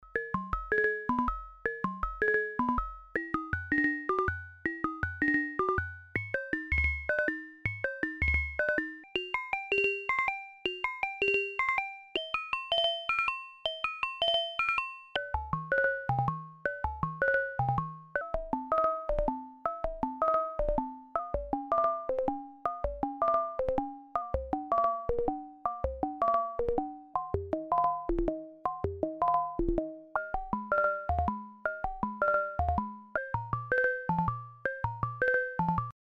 Der Übergang ist je nach Pegel fließend bis zur Verzerrung. Nicht clipping im digitalen Sinne. Vielleicht passt Saturation besser.